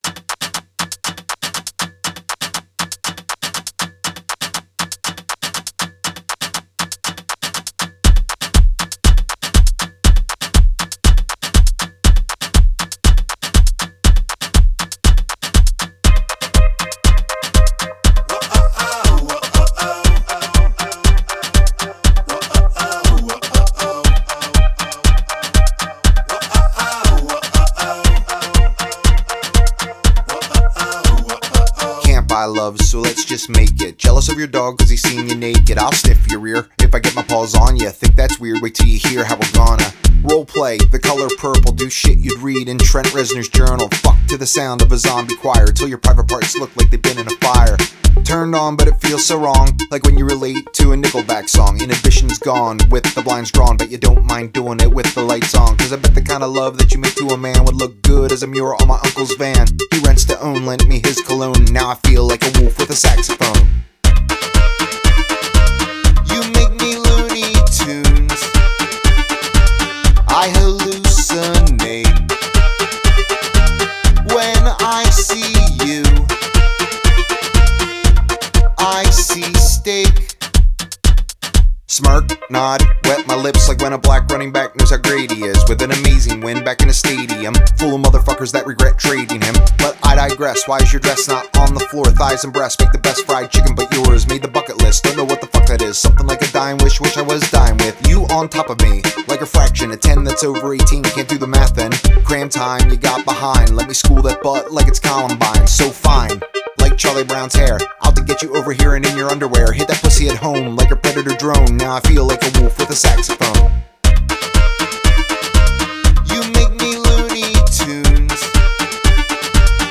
Жанр: Alternative, Rock